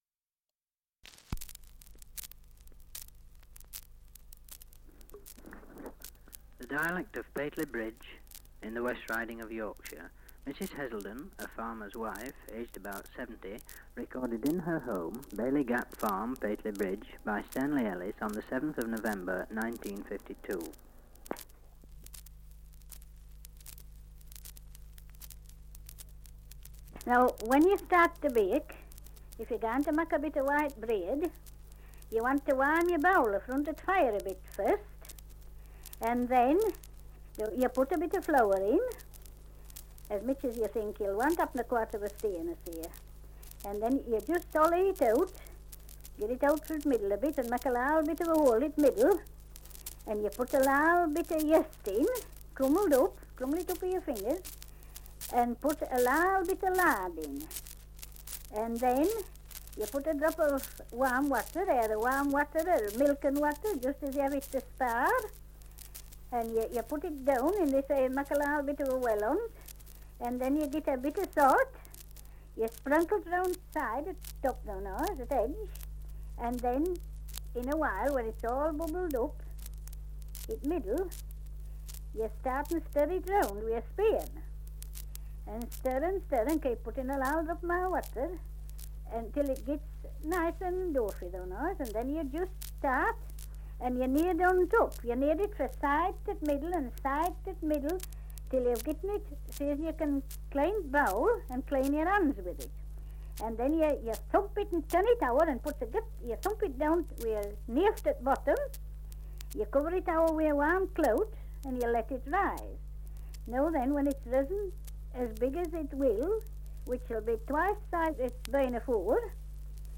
1 - Survey of English Dialects recording in Pateley Bridge, Yorkshire. Survey of English Dialects recording in Horton-in-Ribblesdale, Yorkshire
78 r.p.m., cellulose nitrate on aluminium